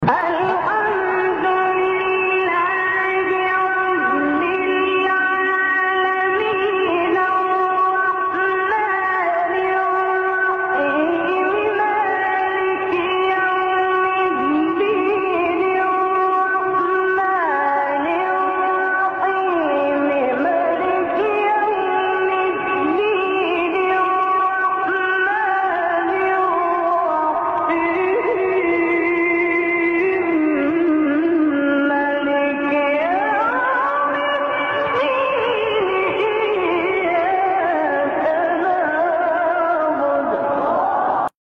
تلاوت زیبای حمد
مقام : بیات